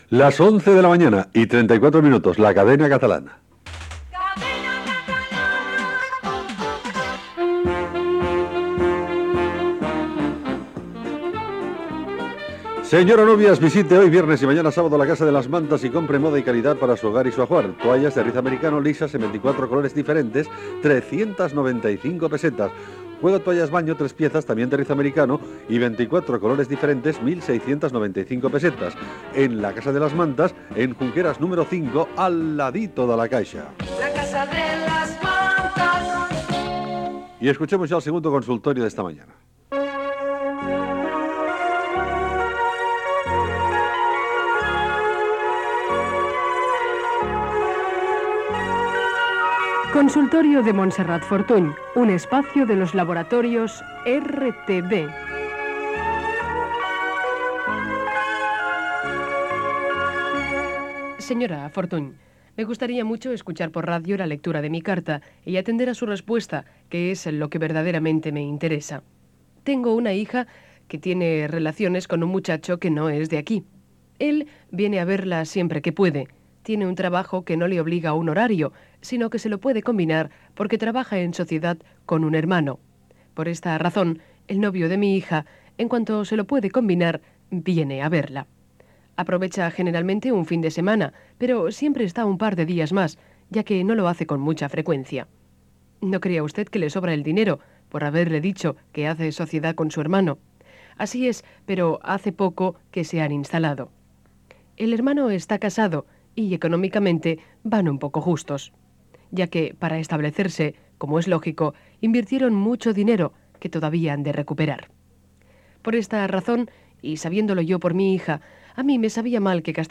Hora, indicatiu, publicitat en directe
Entreteniment